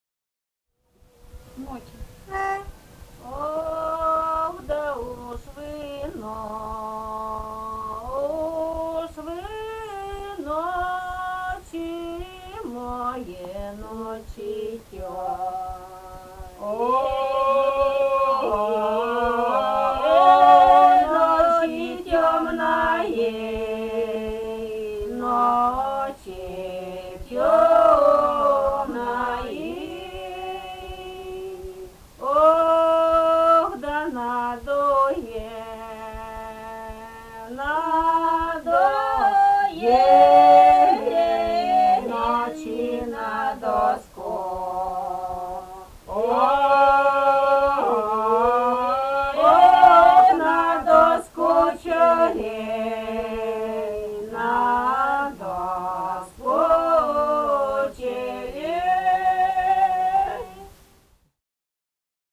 Русские народные песни Владимирской области 3а. Ох, да уж вы, ночи тёмные (лирическая протяжная) с. Пополутово Муромского района Владимирской области.